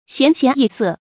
贤贤易色 xián xián yì sè
贤贤易色发音
成语注音 ㄒㄧㄢˊ ㄒㄧㄢˊ ㄧˋ ㄙㄜˋ